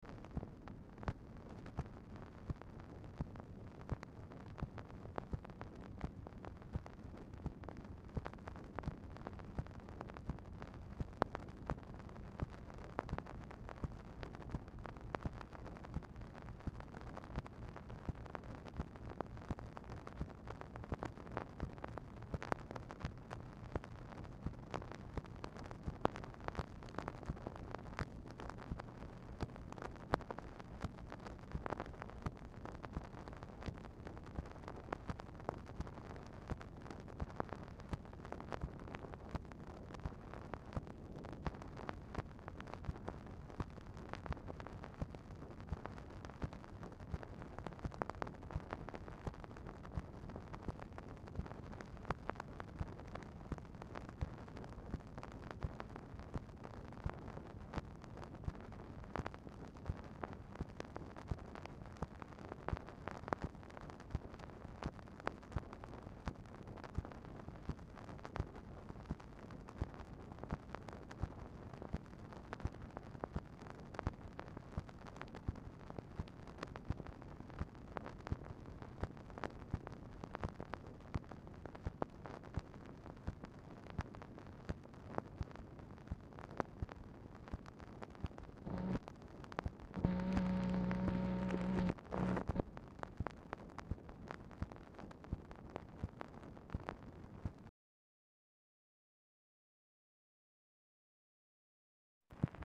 Telephone conversation # 12302, sound recording, MACHINE NOISE, 10/5/1967, time unknown | Discover LBJ
PRIMARILY BLANK, UNGROOVED SECTION OF THE DICTABELT FOLLOWING RECORDING OF REF #12301; SMALL PORTION AT THE END OF THIS RECORDING CONTAINS RECORDED MACHINE NOISE
Dictation belt